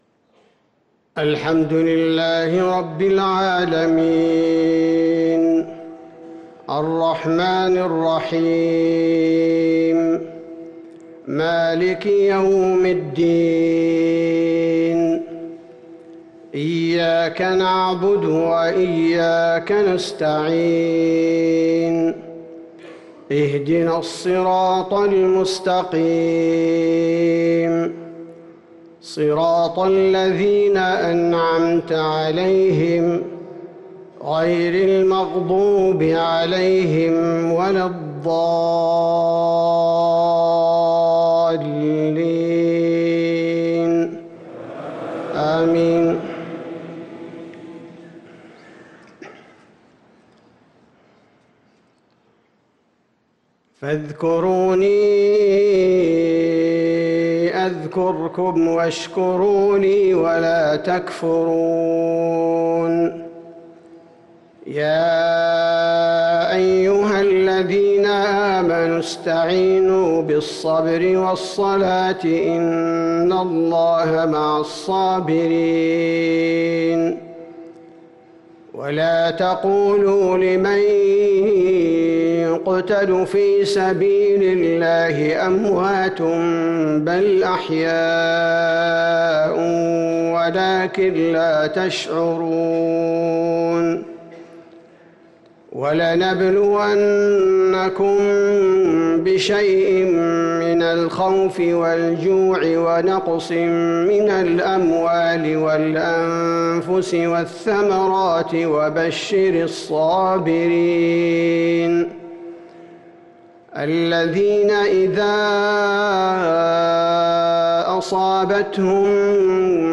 صلاة المغرب للقارئ عبدالباري الثبيتي 25 ربيع الآخر 1444 هـ
تِلَاوَات الْحَرَمَيْن .